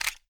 glock20_magout_empty.wav